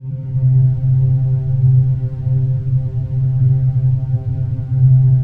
AMB SPACE.wav